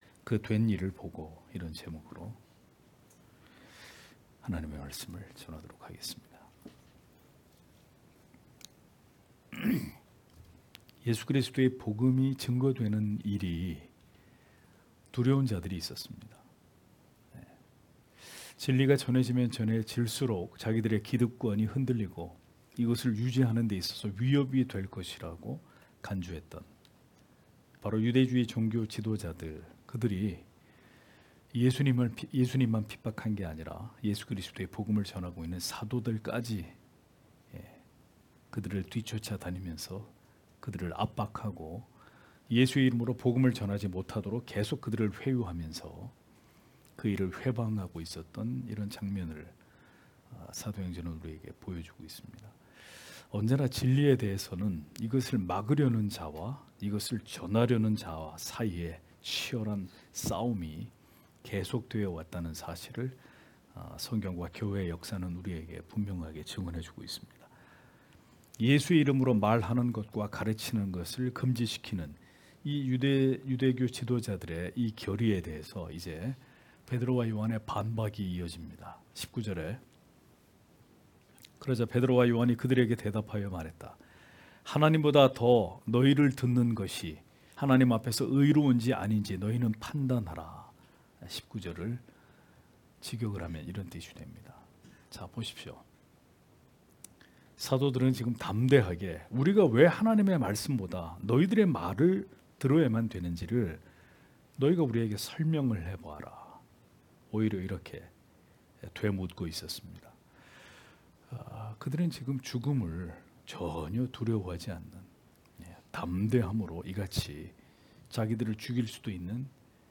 금요기도회 - [사도행전 강해 27]그 된 일을 보고 (행 4장 19-22절)